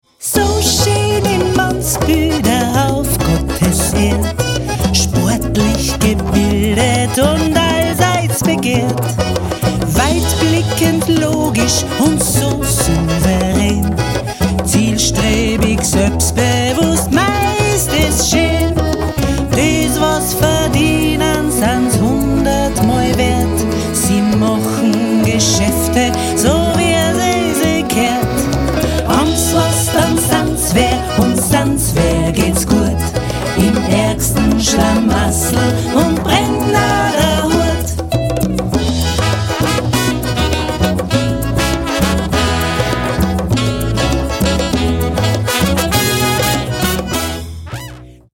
Gitarre, Bass, Ukulele, Gesang
Gambe
Akkordeon
Geige
Schlagzeug, Perkussion
Klavier, Saxophone, Flöten
Trompete
Harfe